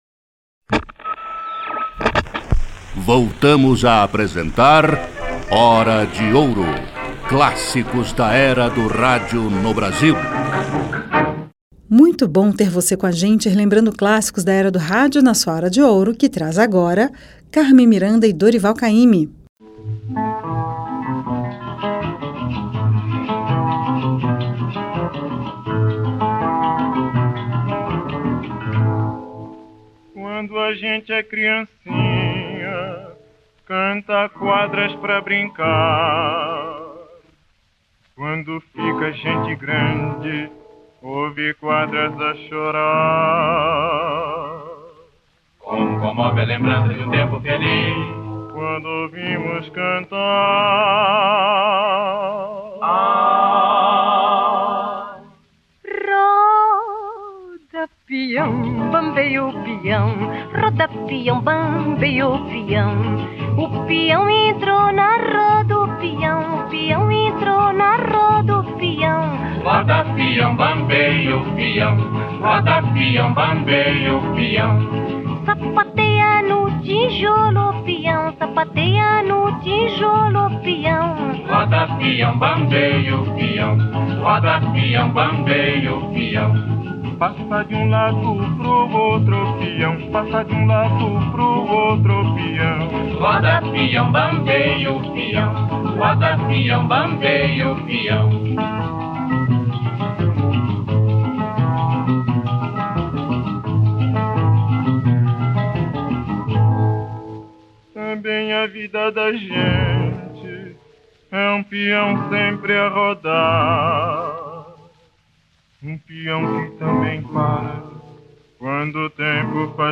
Música Brasileira
Samba